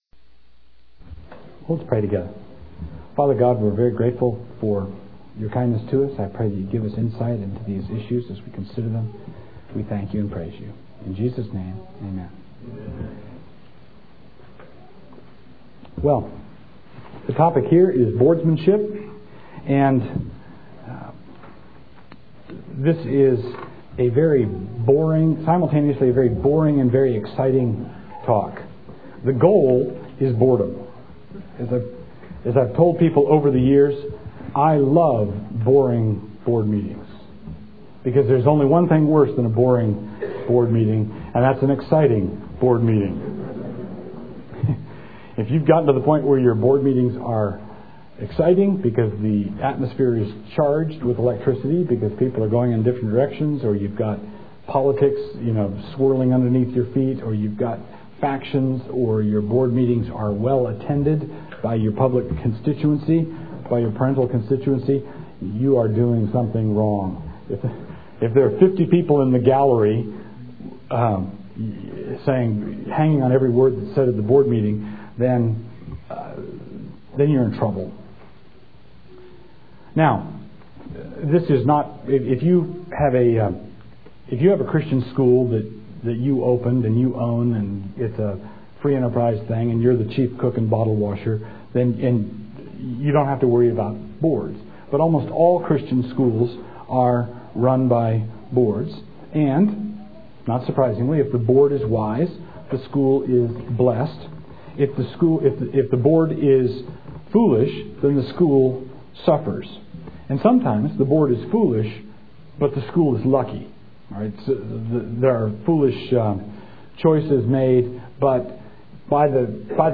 2001 Workshop Talk | 0:56:54 | Leadership & Strategic